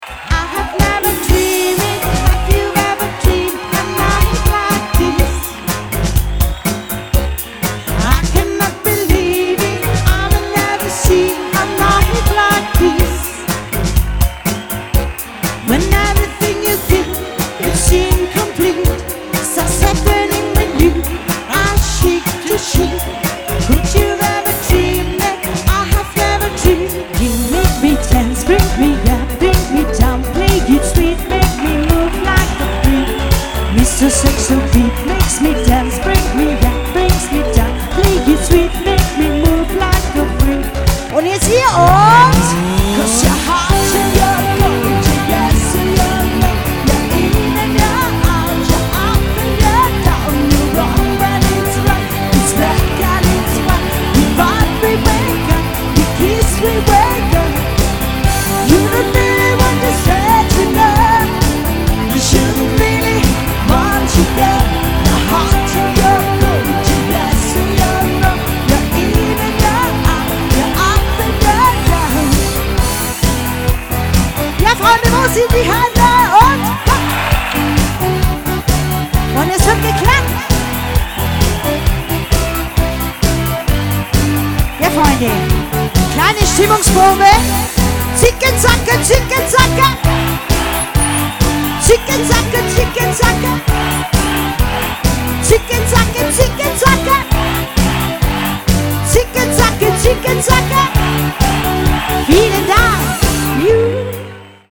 • moderne Popsongs (diverse)